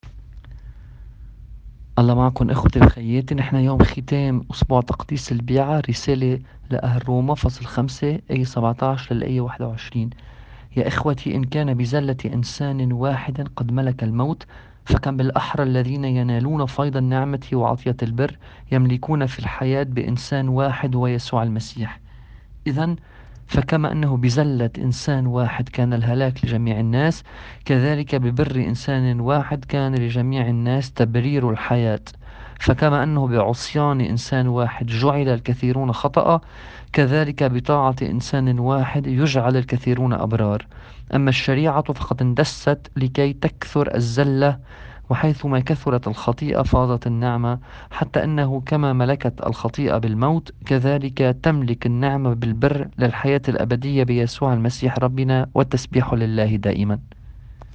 تأمّل في إنجيل يوم ٧ تشرين الثاني ٢٠٢٠.mp3